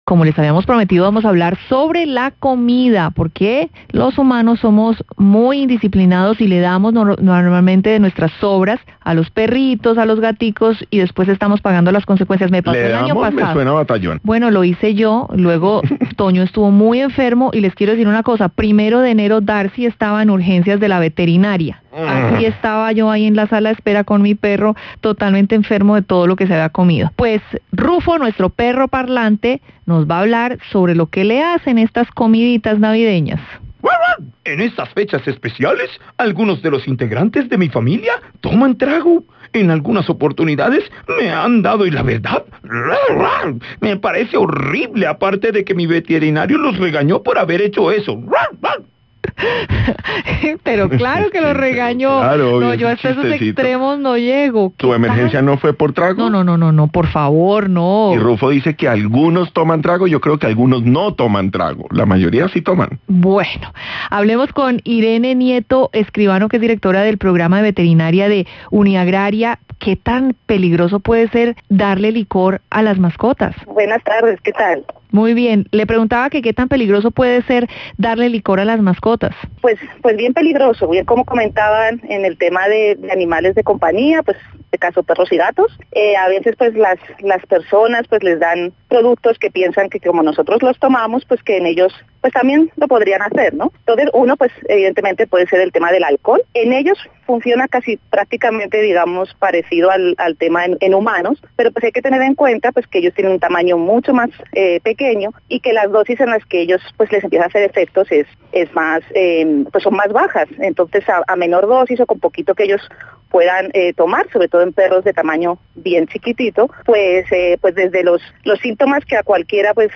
Entrevista a la directora del programa Veterinaria – Caracol Radio